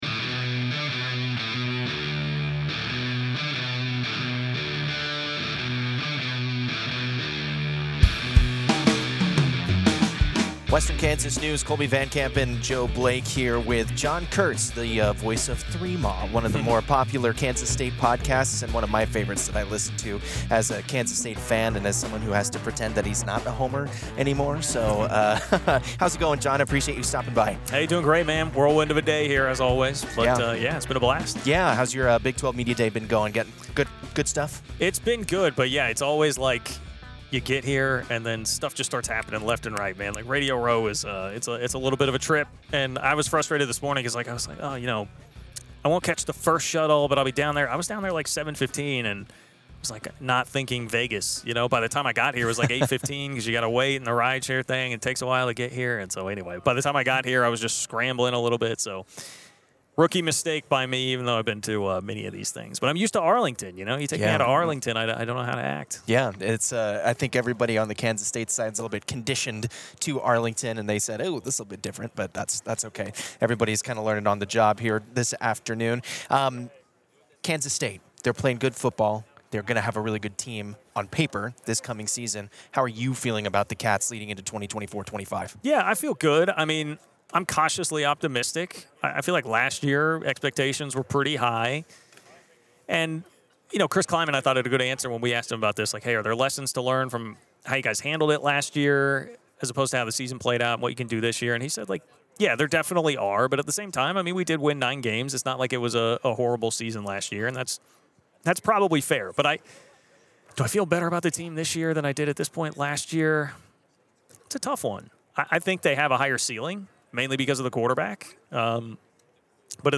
Las Vegas, NV – Western Kansas News sports staff spent this week at the Big 12 Media Days covering the Kansas State Wildcats and the Kansas Jayhawks, as well as acquiring exclusive interviews with other programs and members of the media.